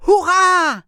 traf_hurra.wav